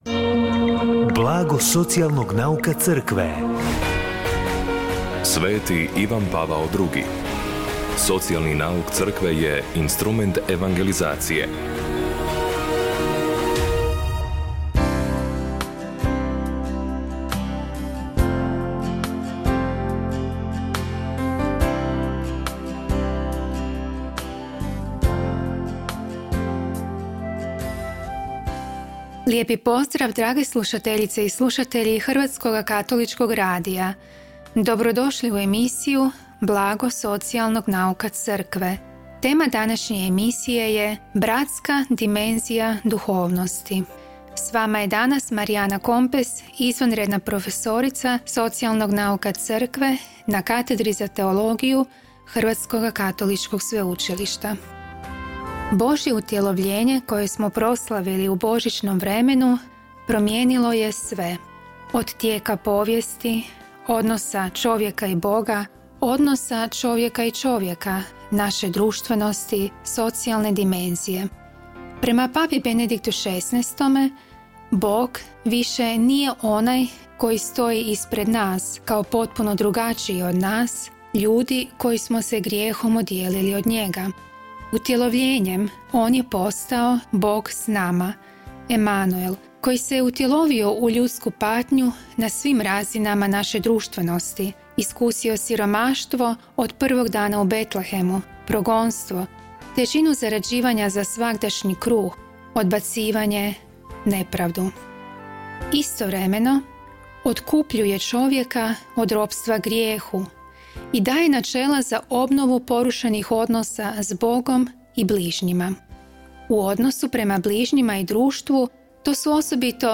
Emisiju na valovima HKR-a “Blago socijalnog nauka Crkve” subotom u 16:30 emitiramo u suradnji s Centrom za promicanje socijalnog nauka Crkve Hrvatske biskupske konferencije.